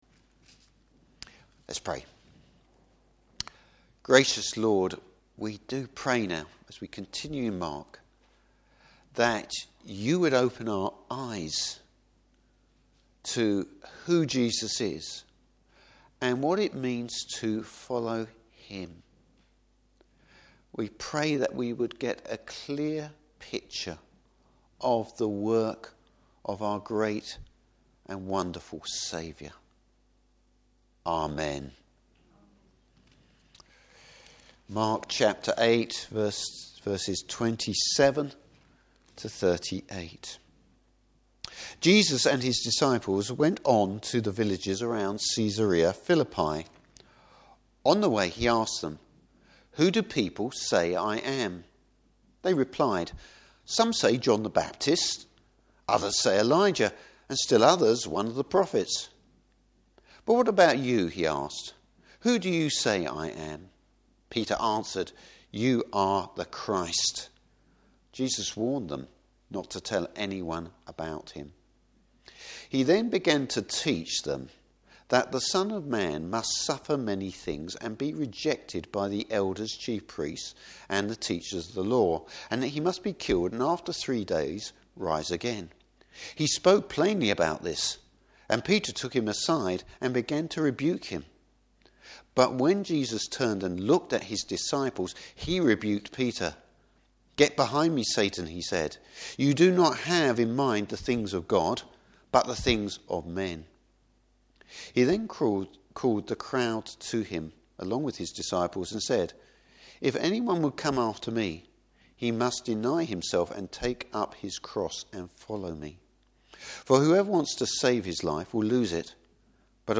Service Type: Morning Service Jesus.